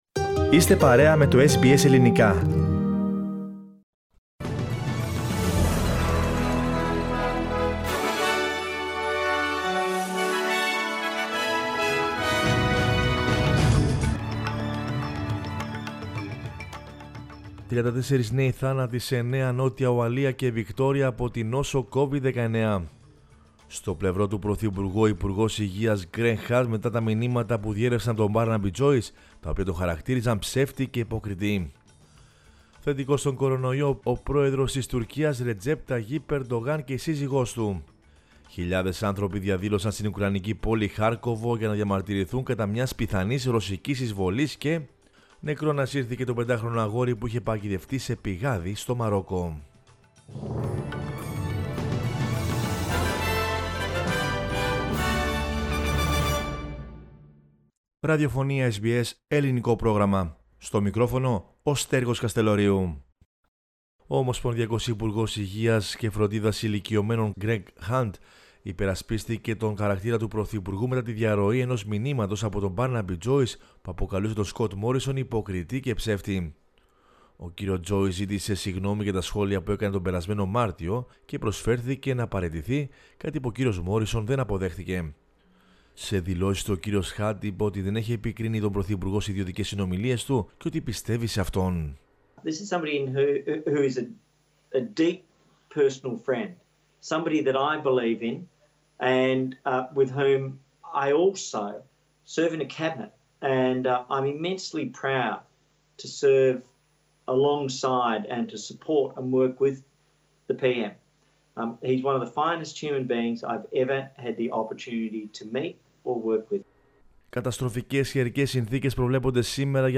News in Greek from Australia, Greece, Cyprus and the world is the news bulletin of Sunday 06 February 2022.